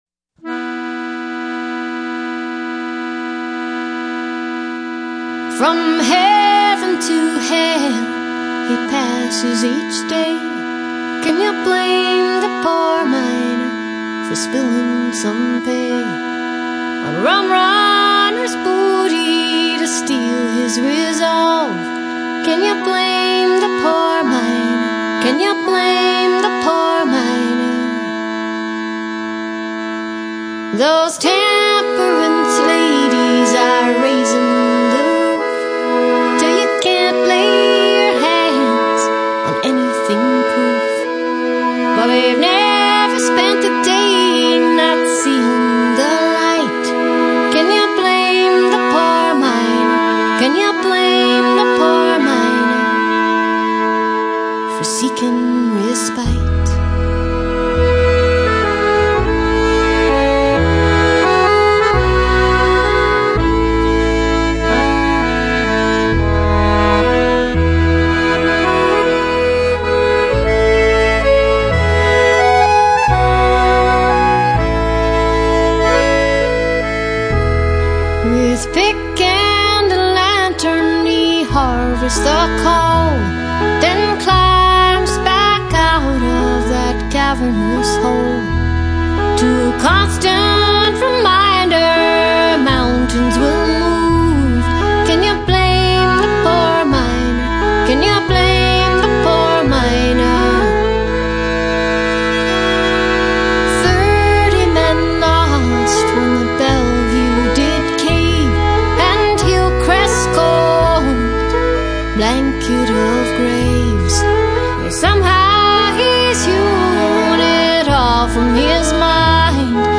Canadian folk singer/accordionist